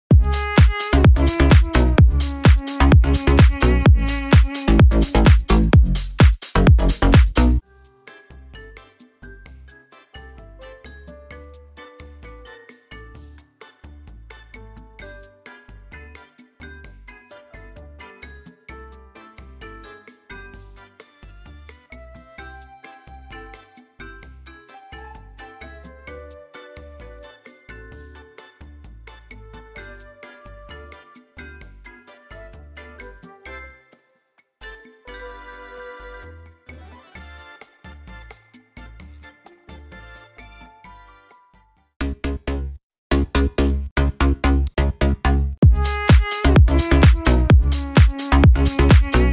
“Sound Design”🎛🎚 - Slappy Bass